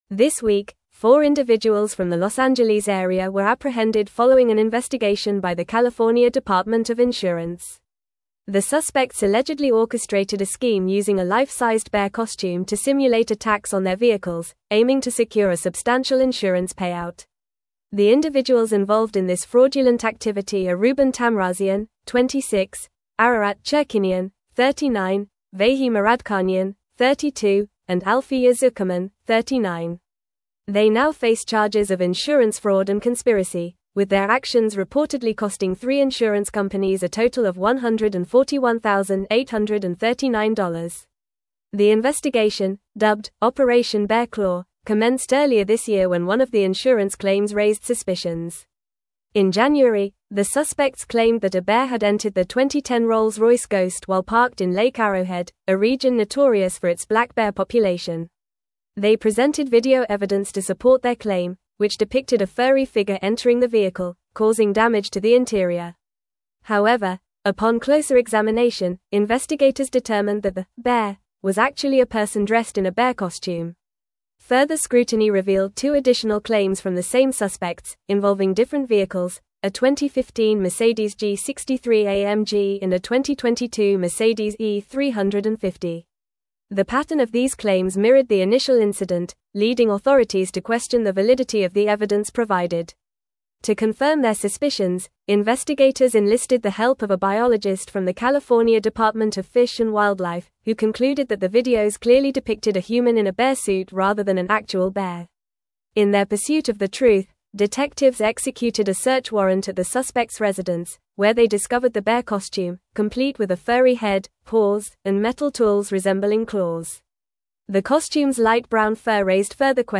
Fast
English-Newsroom-Advanced-FAST-Reading-Suspects-Arrested-for-Staging-Bear-Attack-Insurance-Fraud.mp3